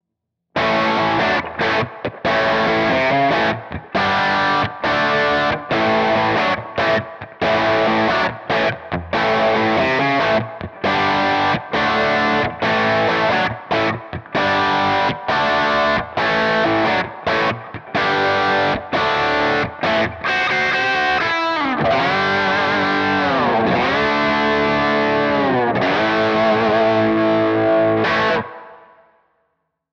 Der Bridge-Pickup hat vor allem im Highgain-Territorium seine Stärken.
Drive Sound, Bridge Humbucker